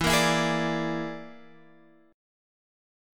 E7b5 chord